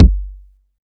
KICK.20.NEPT.wav